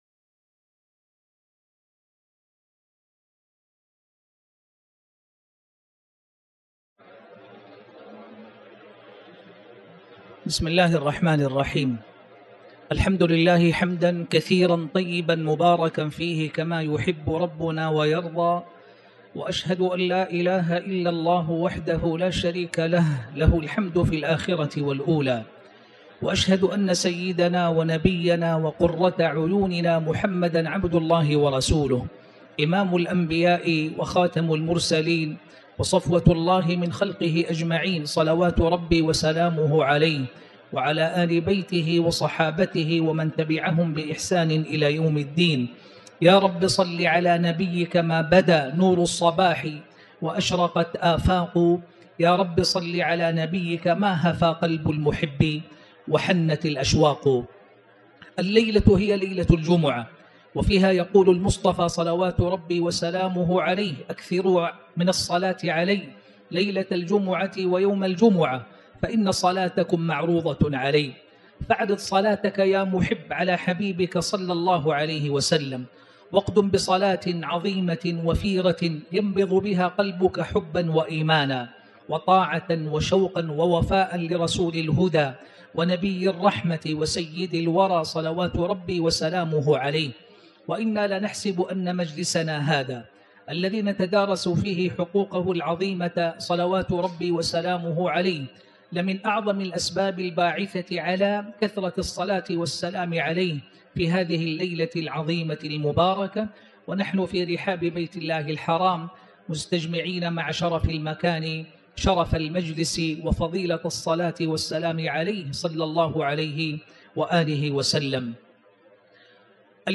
تاريخ النشر ٤ جمادى الأولى ١٤٤٠ هـ المكان: المسجد الحرام الشيخ